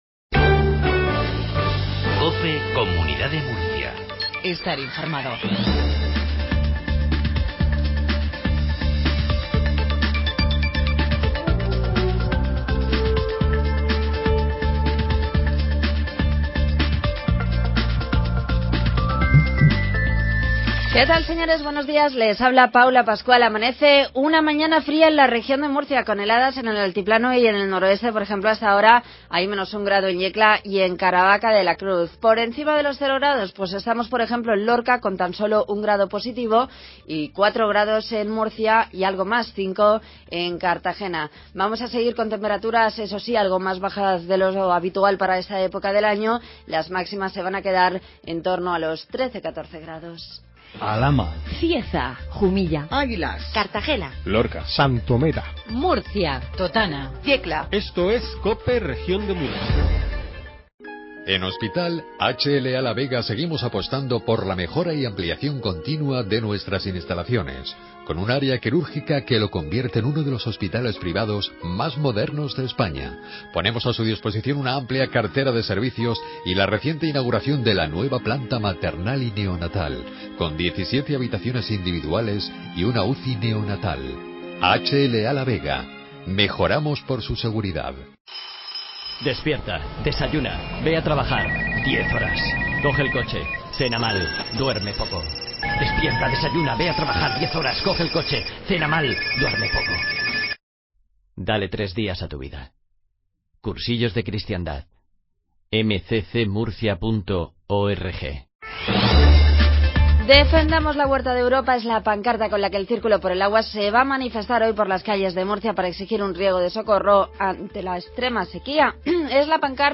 INFORMATIVO MATINAL MURCIA 07.20